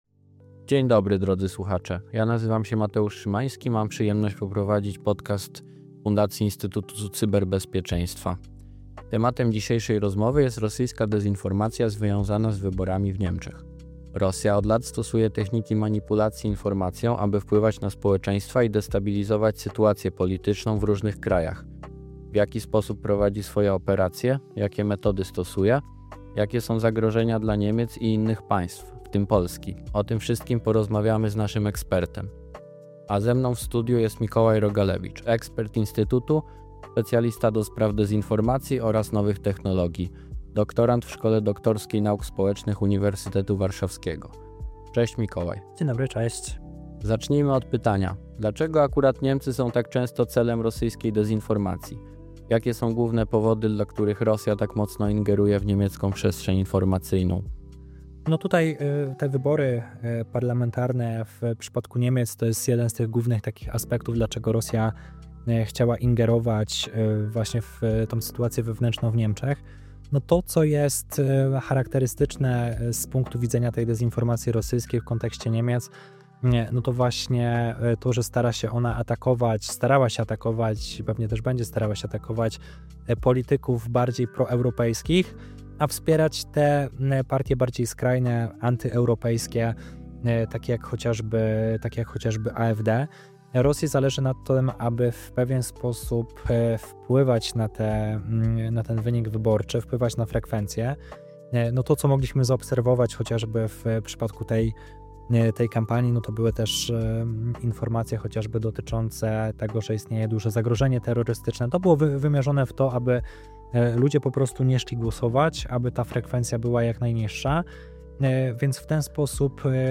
O tych zagadnieniach rozmawiamy z naszym ekspertem